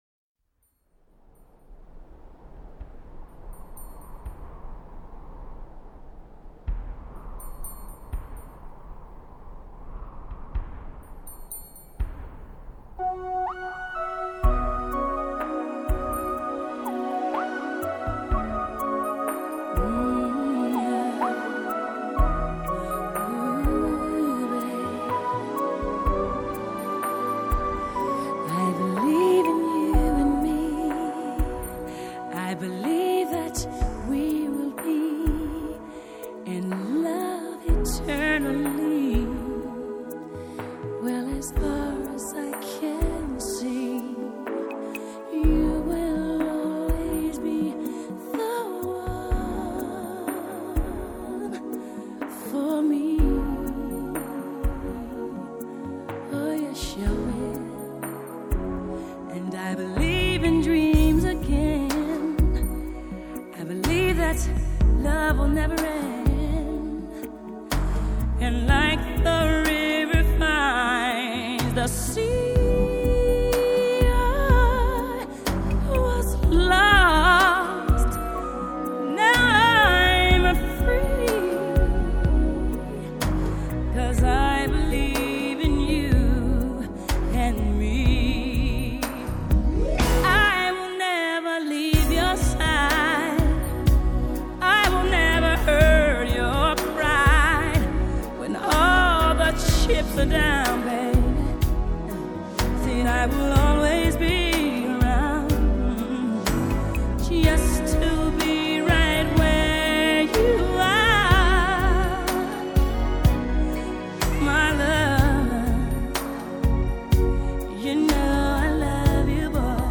Gospel, Pop, Soundtrack